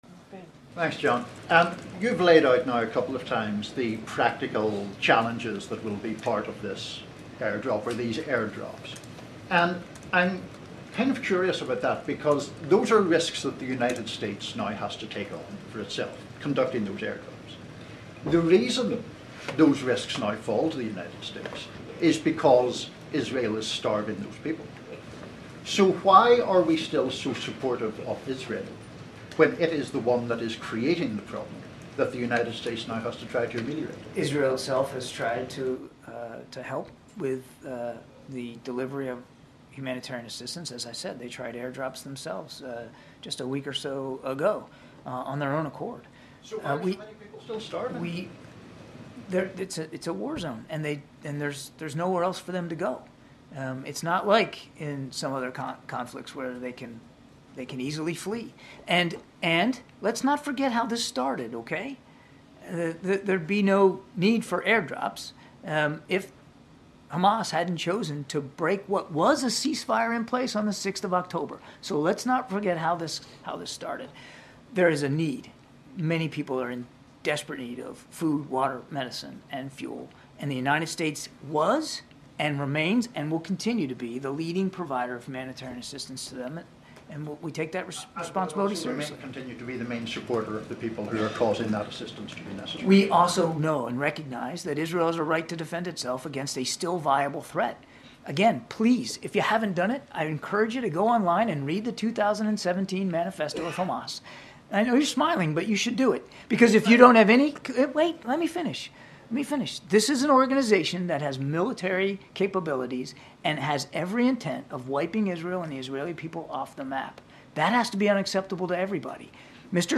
Why Are We Still So Supportive Of Israel?: Reporter Confronts Kirby About Gazas Starving